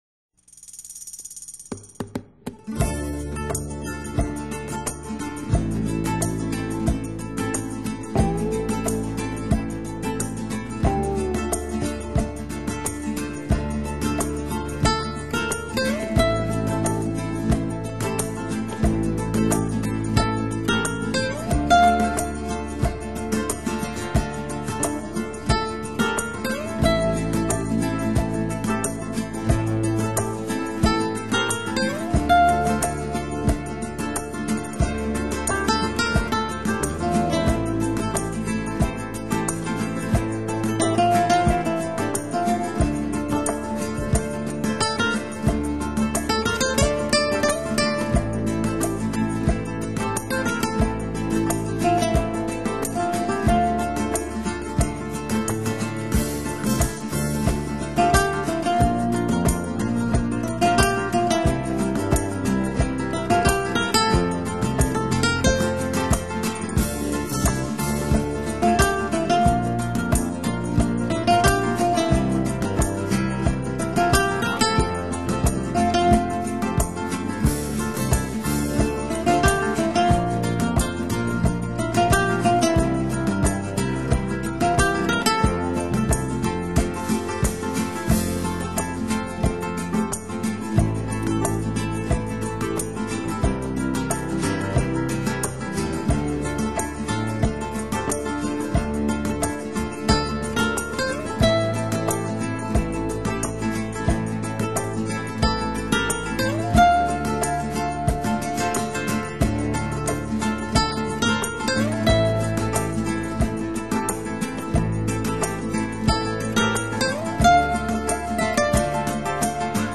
音乐类别：精神元素